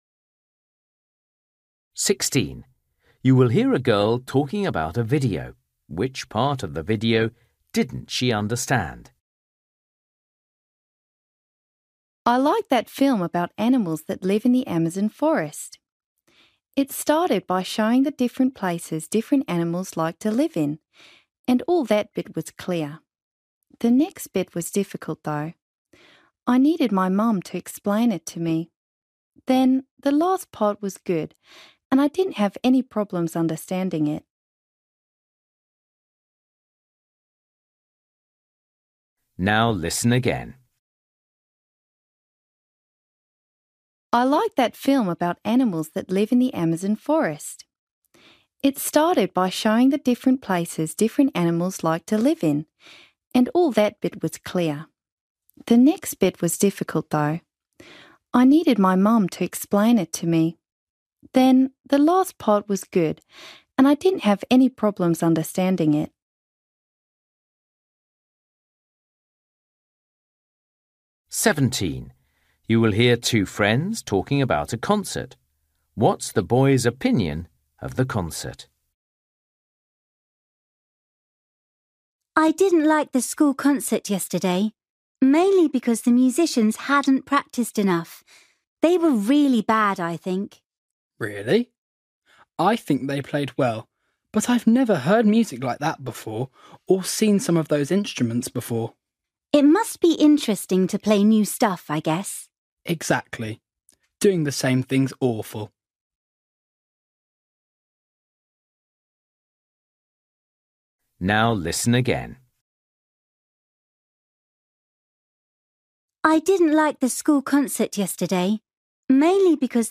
Listening: everyday short conversations
16   You will hear a girl talking about a video. Which part of the video didn’t she understand?
17   You will hear two friends talking about a concert. What’s the boy’s opinion of the concert?
19   You will hear a teacher talking to his class. What information is he giving his student?